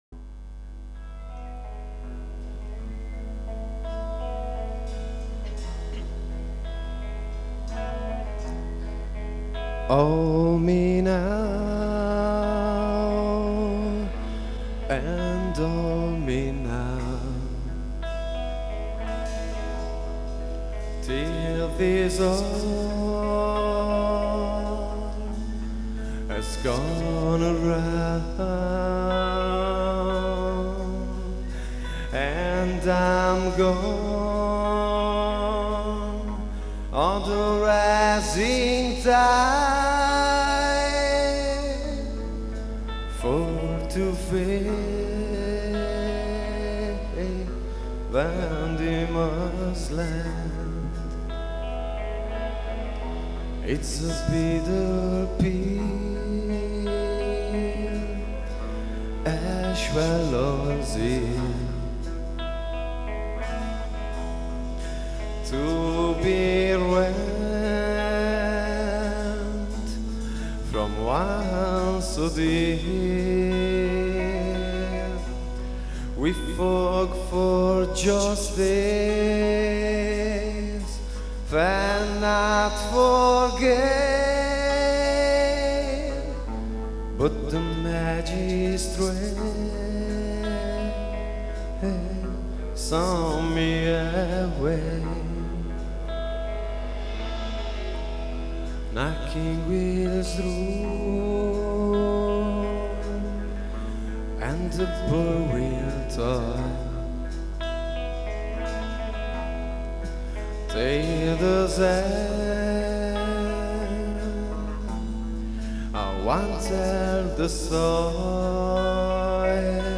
Download   CD live (1983-1993) del 1998